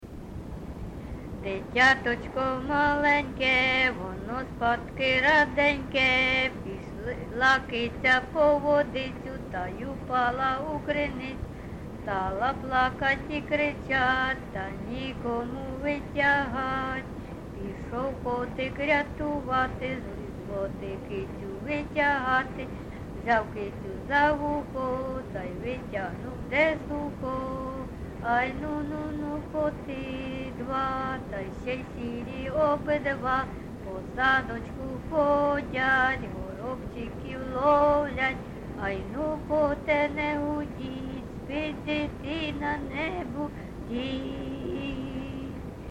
ЖанрКолискові
Місце записус. Харківці, Миргородський (Лохвицький) район, Полтавська обл., Україна, Полтавщина